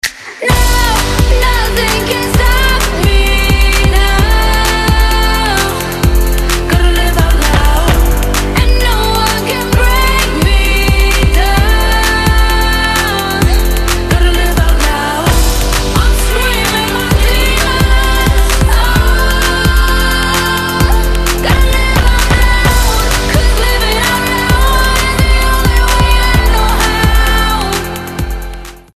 • Качество: 256, Stereo
поп
громкие
женский вокал
dance
Electropop